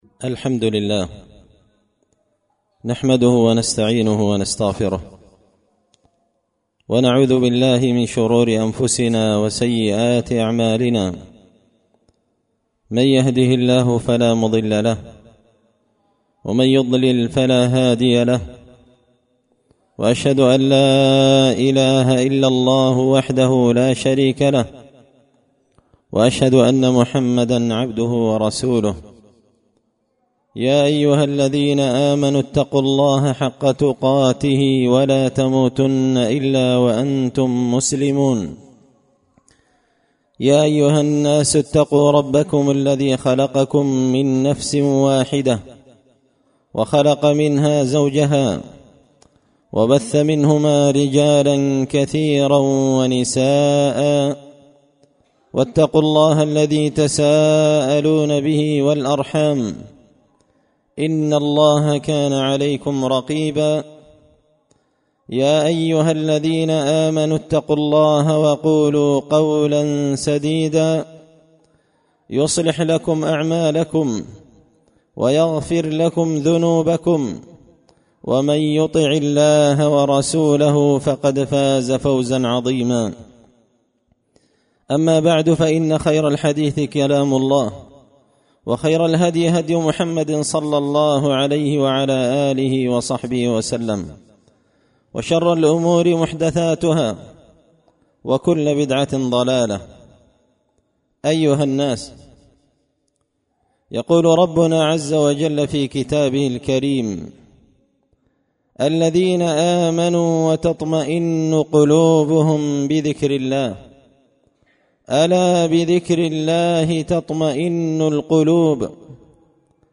خطبة جمعة بعنوان ذخيرة العقبى فيمن يستحق شجرة طوبى
دار الحديث بمسجد الفرقان ـ قشن ـ المهرة ـ اليمن